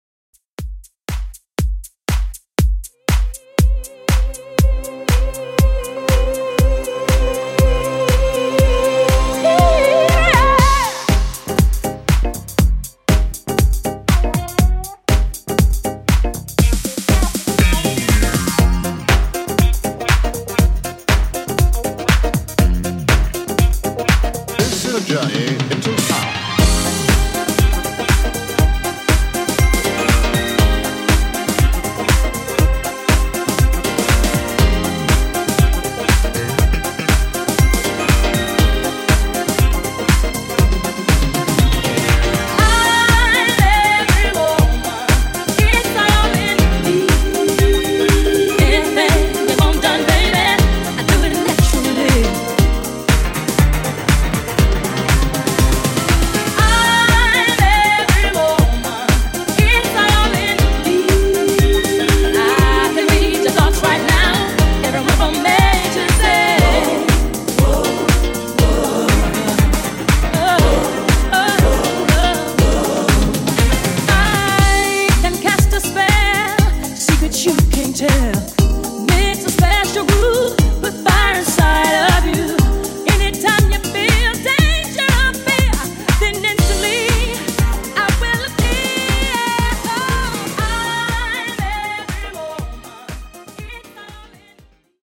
90s Funk Redrum)Date Added